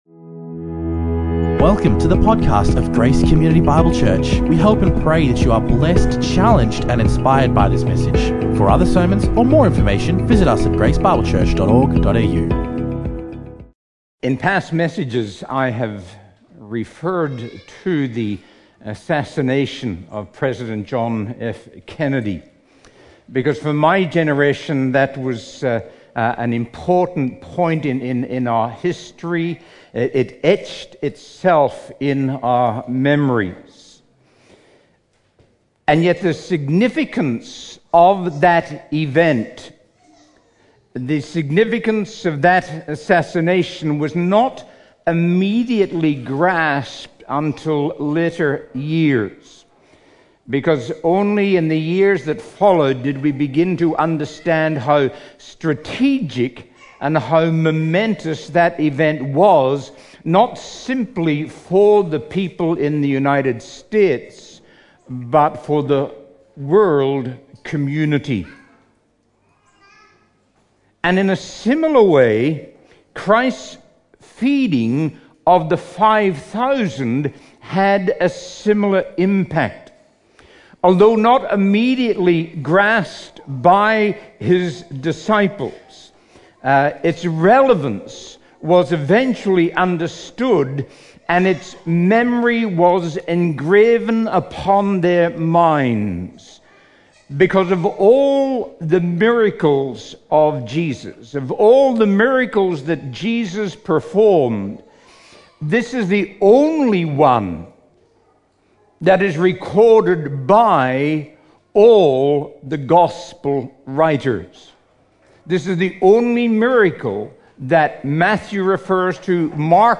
Guest preacher
recorded live at Grace Community Bible Church